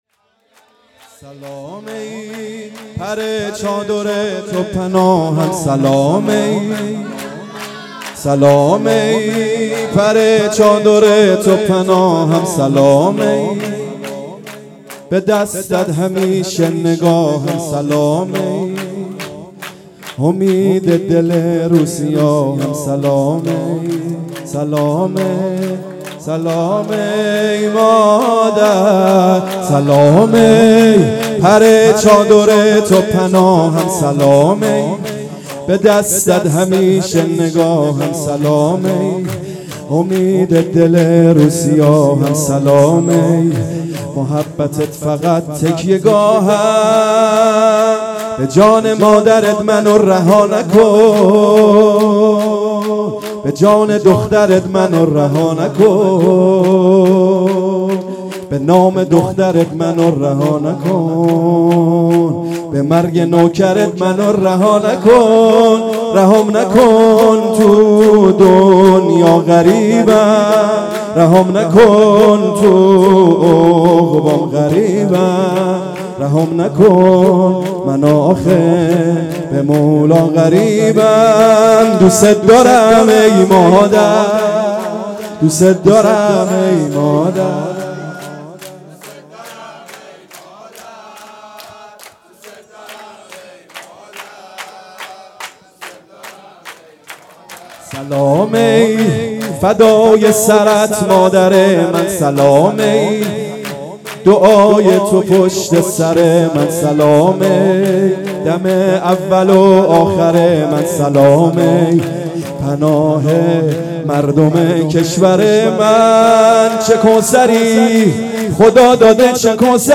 شور - سلام ای پر چادر تو پناهم
جشن ولادت حضرت زهرا سلام الله علیها